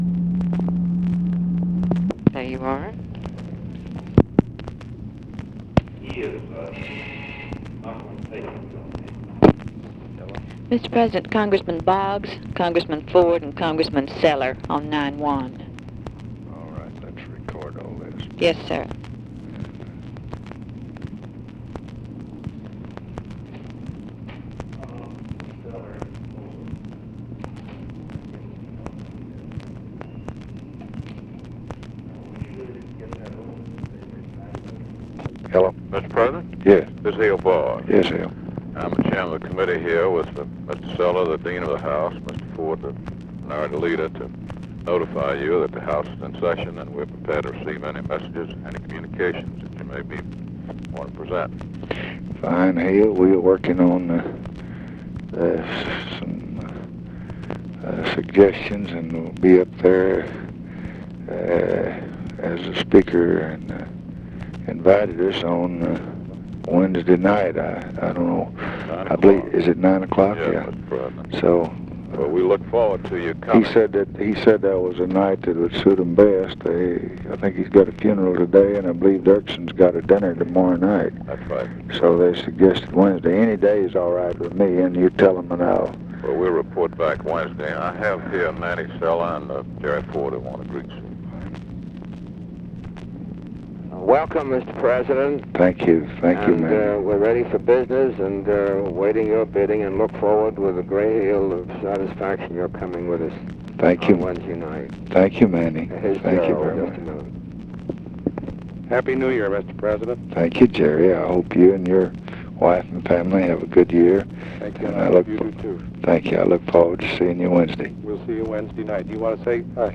Conversation with HALE BOGGS, OFFICE SECRETARY, OFFICE CONVERSATION, EMANUEL CELLER and GERALD FORD, January 10, 1966
Secret White House Tapes